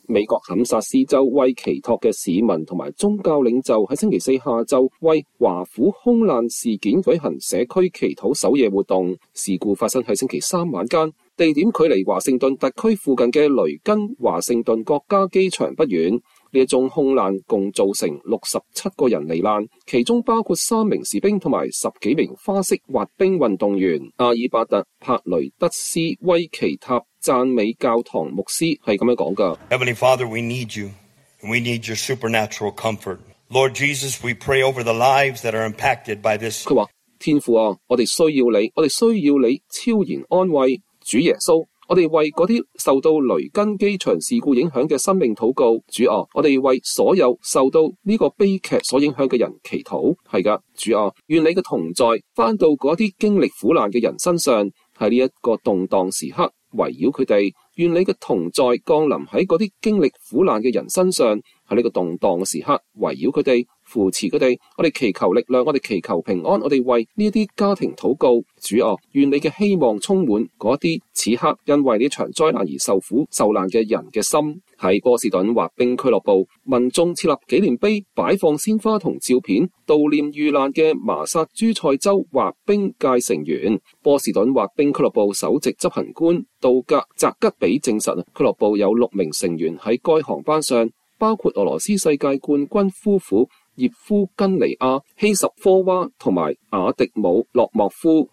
威奇托民衆為華府空難事件舉行祈禱守夜活動。